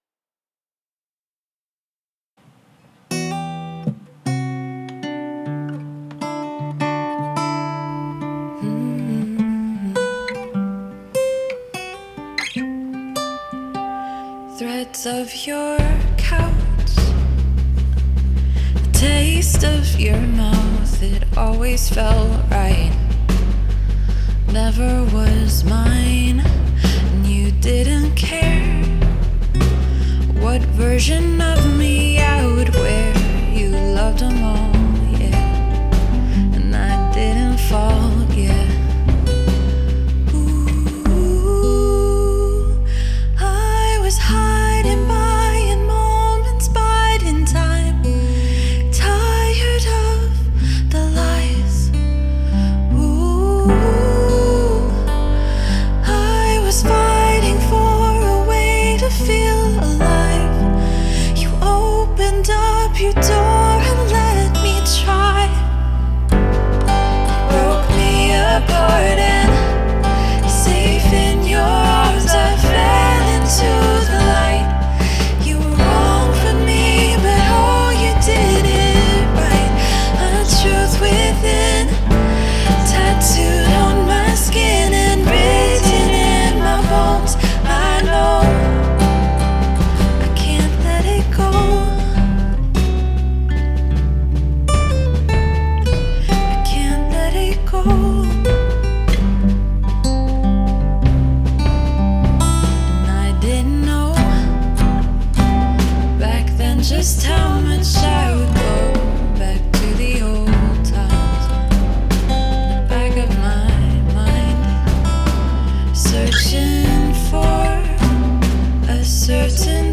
Must include a guest from outside the band singing a vocal harmony
The bass in this is tasty.
The guest harmony vox are stellar. The use of the stereo field is good.
I like the minimalistic arrangement here and the spacious-sounding mix.
The lead vocal is stunning and the guitar work really makes every sparse note count with the piano chords rounding out the arrangement nicely.